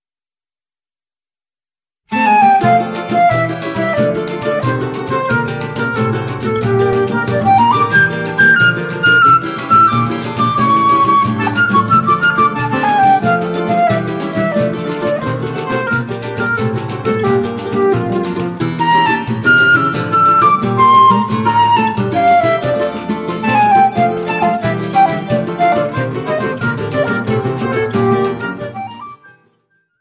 flauta